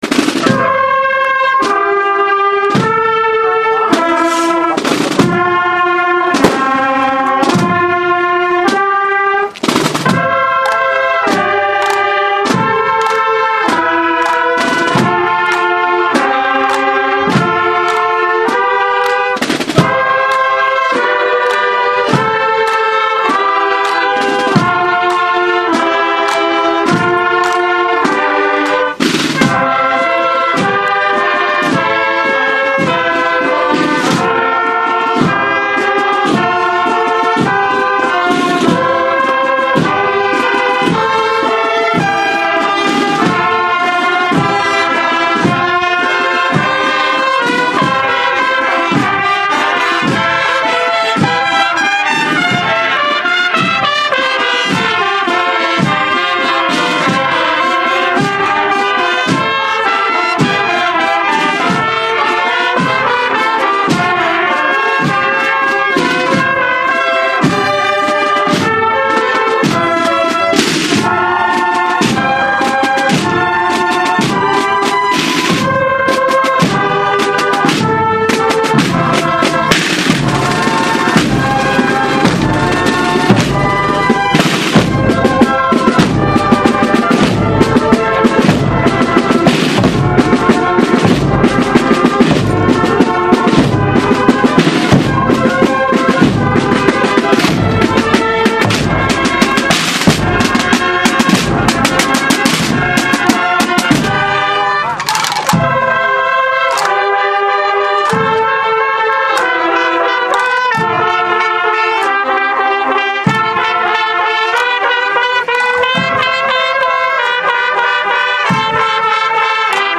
Procesión Raiguero Bajo 2013
El pasado domingo 28 de julio tuvo lugar en la pedanía del Raiguero Bajo una Solemne Procesión con las imágenes de Santiago Apóstol, San Fulgencio y La Purísima, que contó con la asistencia de vecinos, autoridades municipales y eclesiásticas y la banda de cornetas y tambores de la Hermandad de Jesús en el Calvario y Santa Cena.